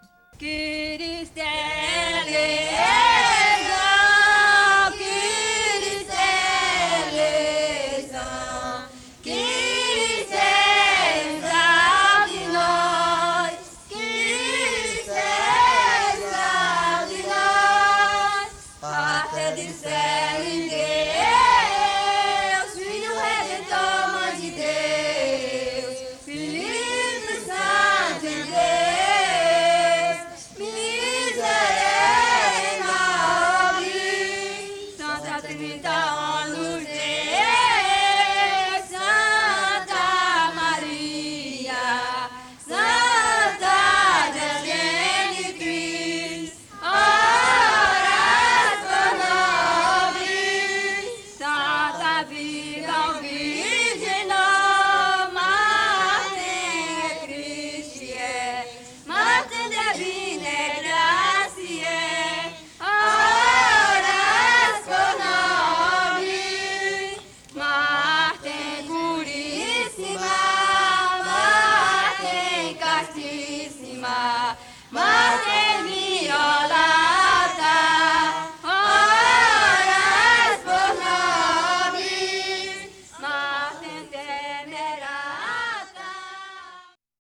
Terço Cantado em Homenagem a Santo Antônio